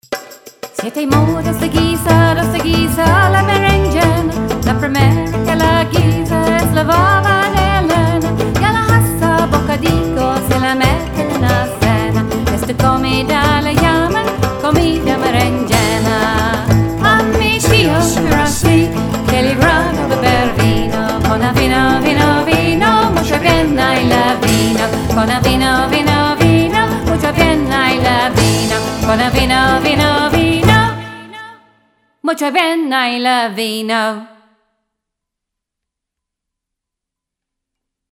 Rich compelling melodies and tight driving rhythm
an eclectic mix of music from Eastern Europe and beyond.